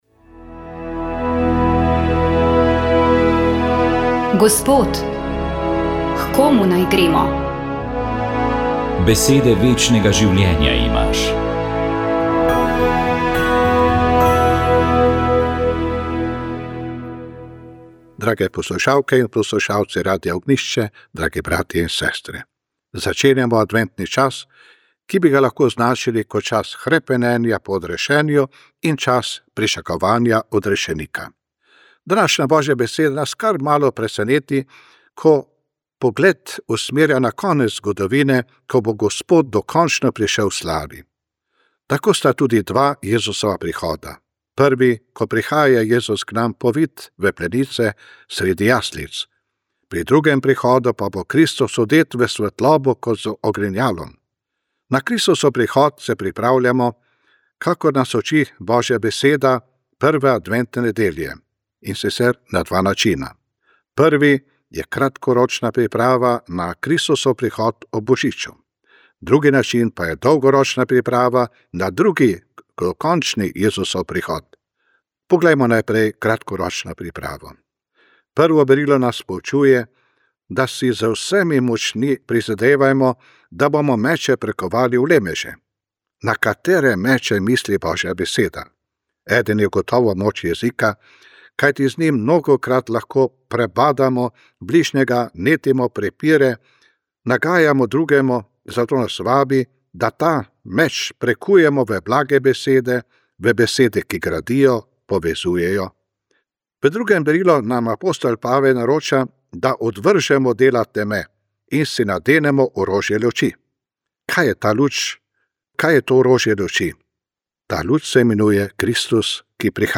Duhovni nagovor
Na prvo adventno nedeljo nas je nagovoril upokojeni ljubljanski nadškof Alojz Uran.